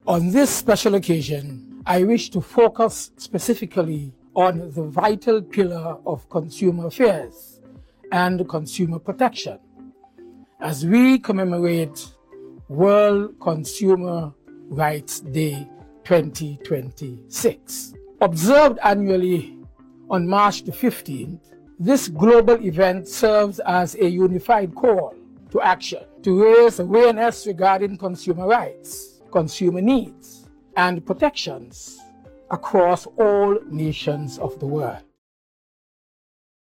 Minister of Trade and Consumer Affairs-St. Kitts, Dr. Denzil Douglas, delivered an address on the occasion and officially opened Consumer Awareness Week (Mar. 15 th to the 20 th ).